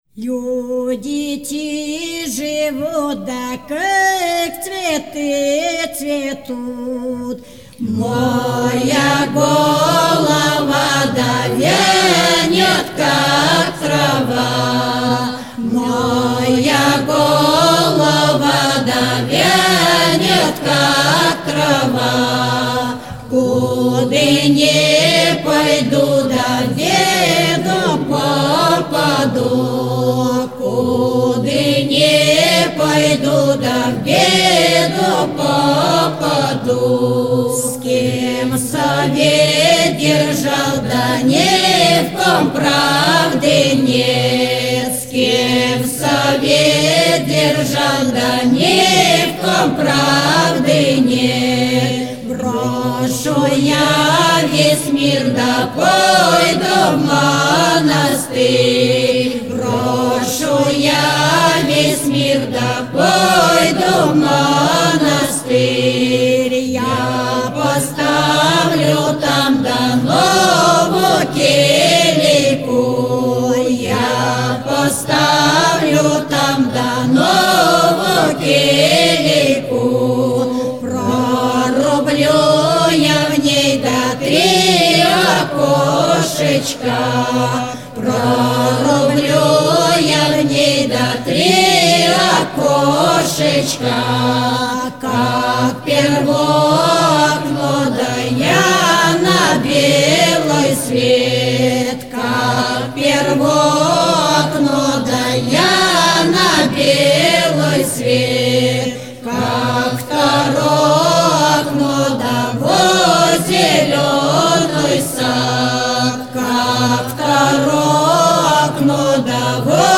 Я люблю русский фольклёр на ютубе слушать Нажмите для раскрытия...